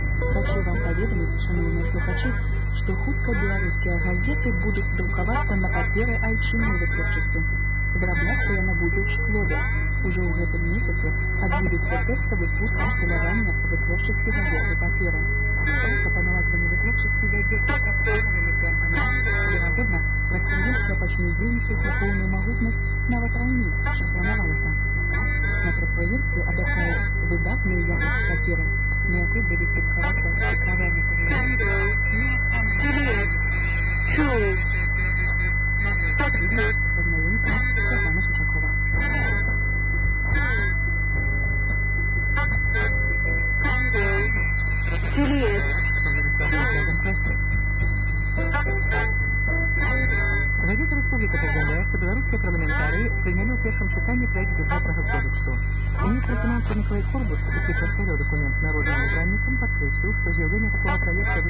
Записи с эфира: 2007 Номерные радиостанции